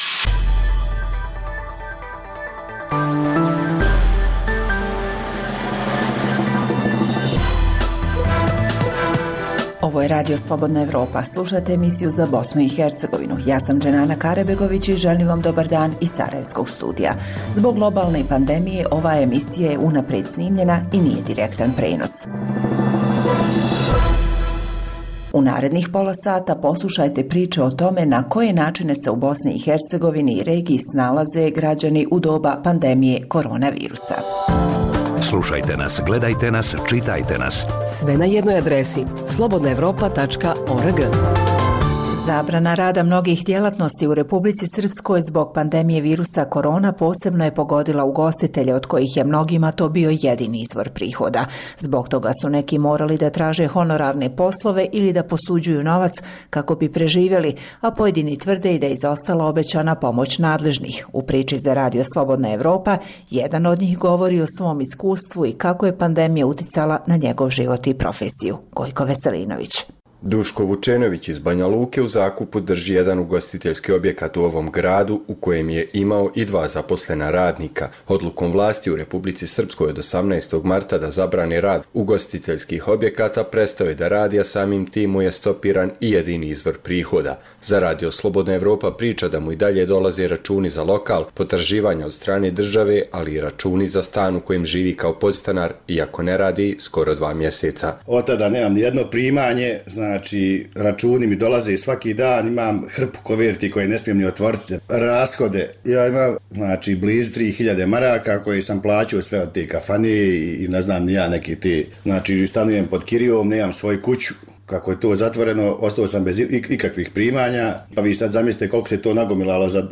Zbog pooštrenih mjera kretanja u cilju sprječavanja zaraze korona virusom, ovaj program je unaprijed snimljen. Poslušajte neke od priča iz raznih krajeva Bosne i Hercegovine.